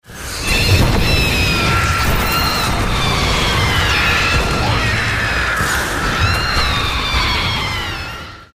Play, download and share CHICKENZZZZZ original sound button!!!!
destiny_omnigul-scream1.mp3